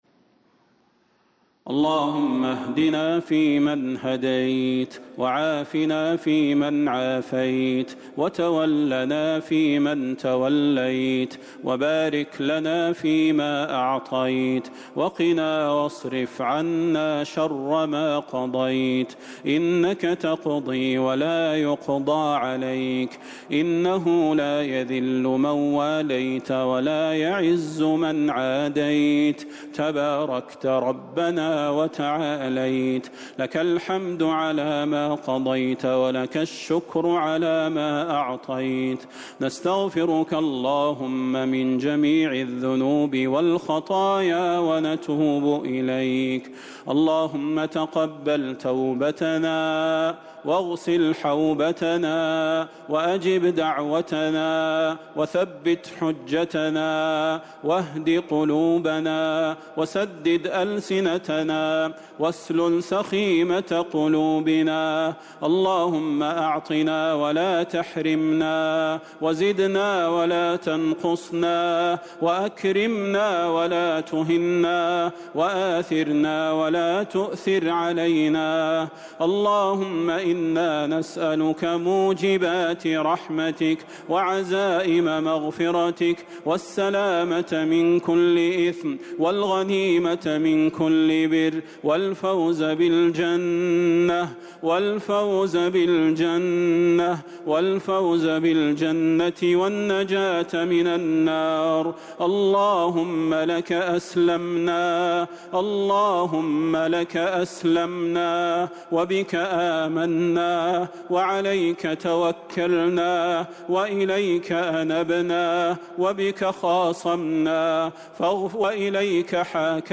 دعاء القنوت ليلة 24 رمضان 1441هـ > تراويح الحرم النبوي عام 1441 🕌 > التراويح - تلاوات الحرمين